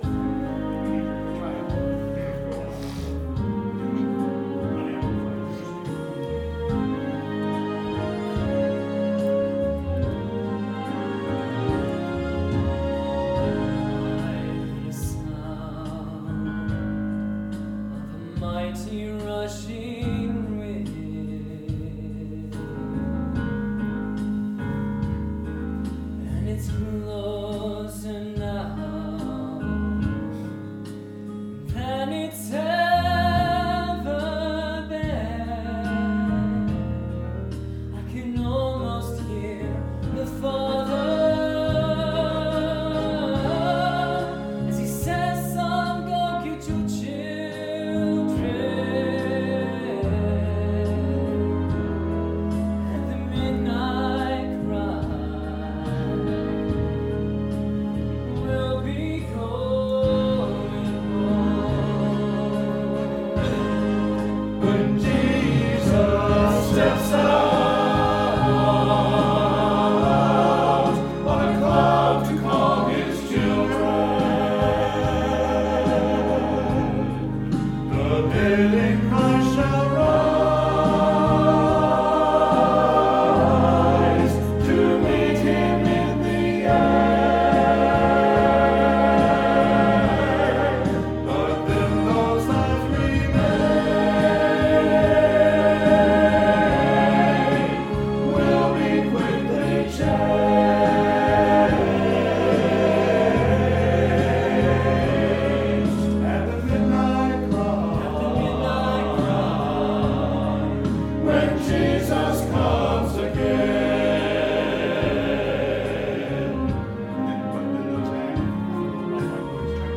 Full recording from rehearsal